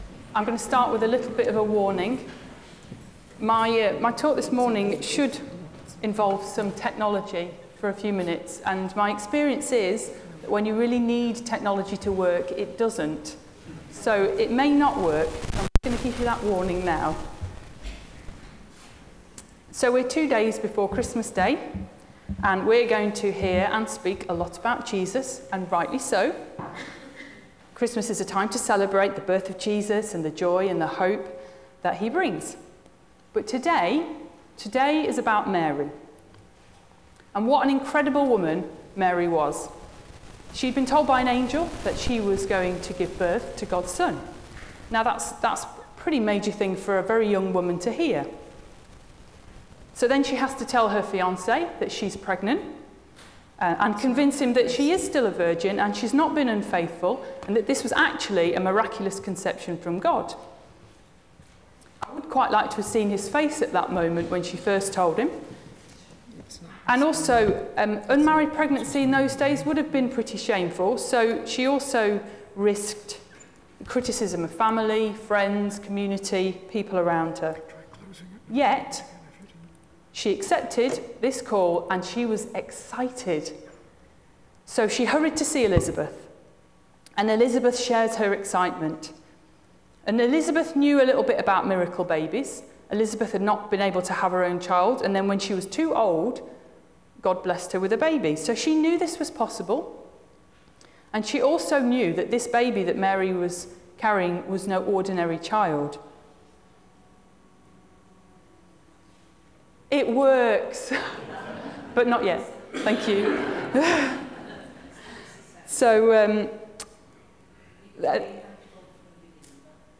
Sermons | St Philip Anglican Church
Guest Speaker